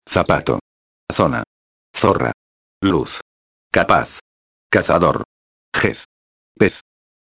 » O "z" (la zeta), em toda América Latina, pronuncia-se sempre como dois "s" em português.
Na Espanha o som corresponde ao "th" do inglês na palavra "with".